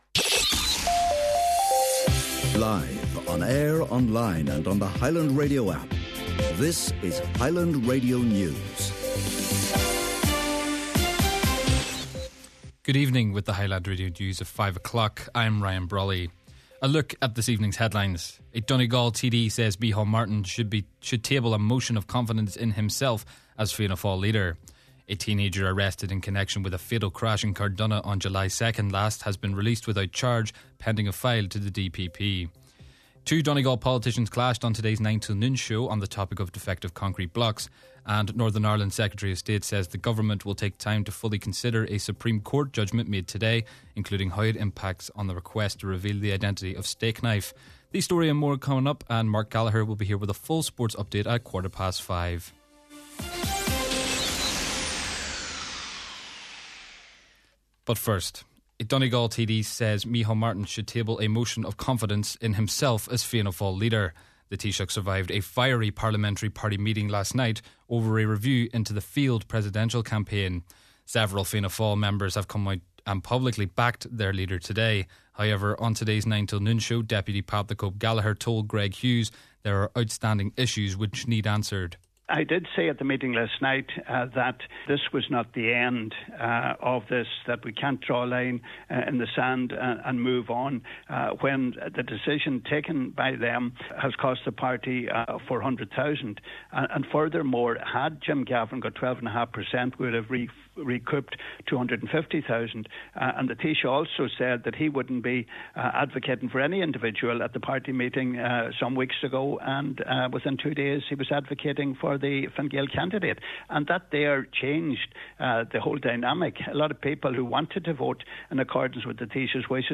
Main Evening News, Sport & Obituary Notices – Wednesday December 17th